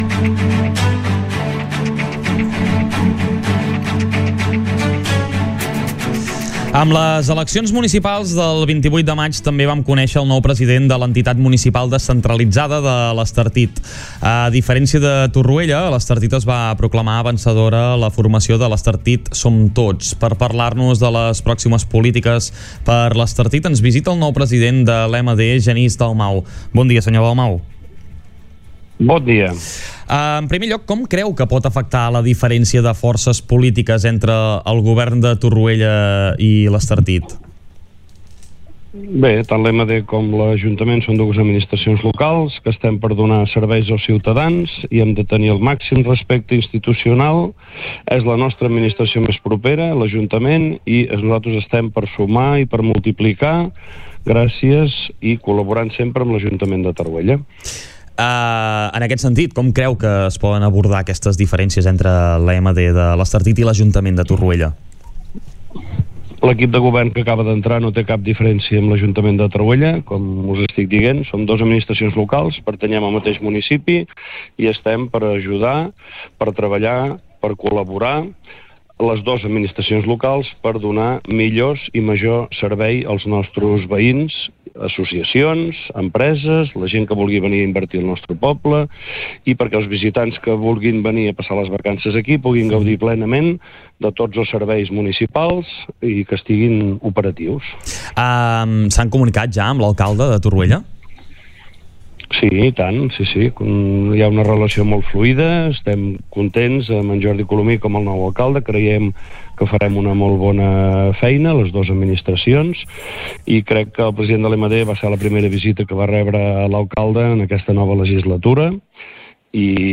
Entrevistes Supermatí
Entrevista-completa-Genis-Dalmau.mp3